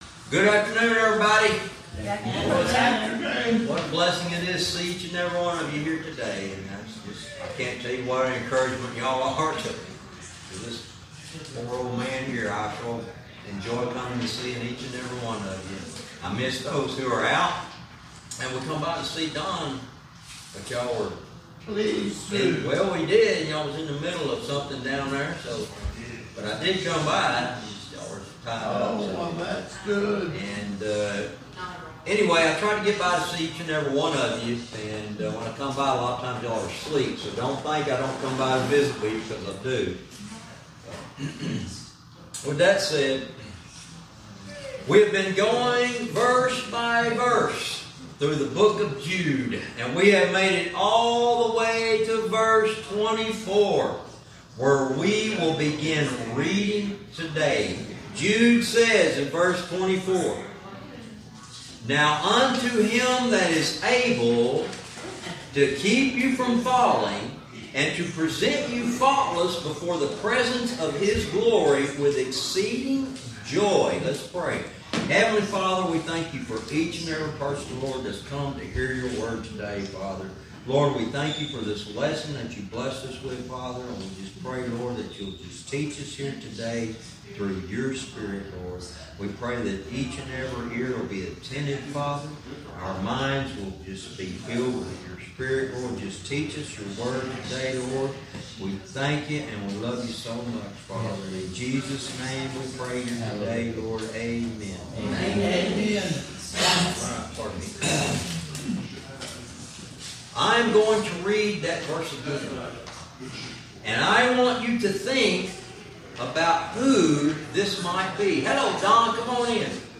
Verse by verse teaching - Jude lesson 104 verse 24